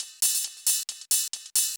K-7 Hi Hats.wav